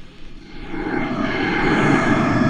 ANIMALNOIS.wav